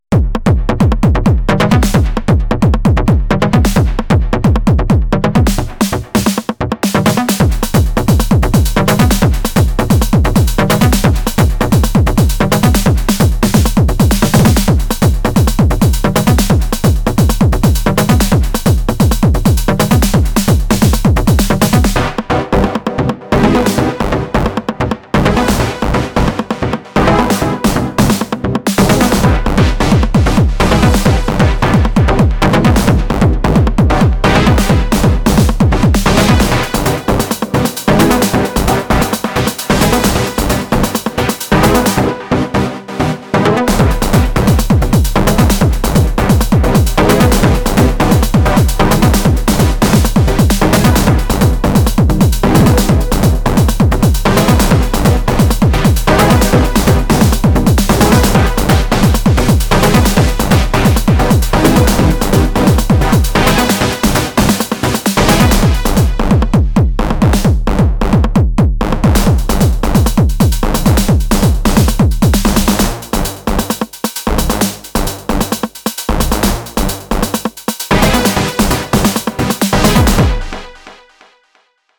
Another jam, all CoreVault, all too much fun :wink: